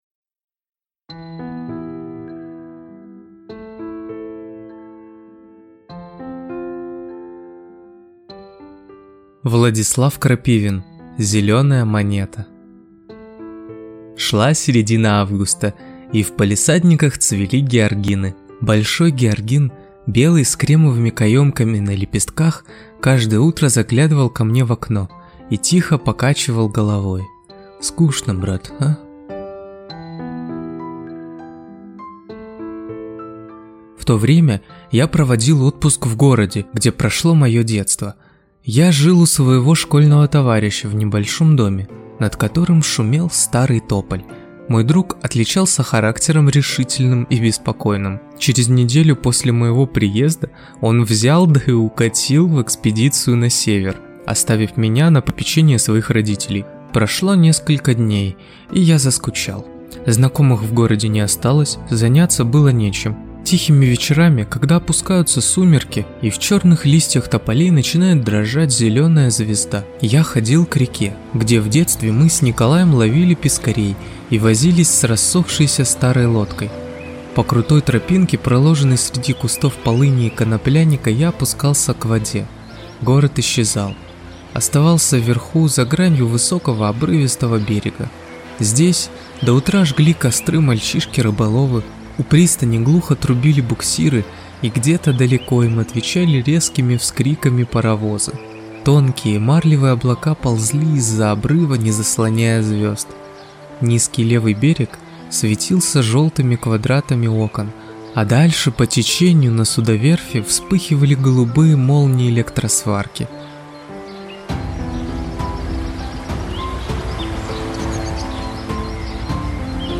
Аудиокнига Зелёная монета | Библиотека аудиокниг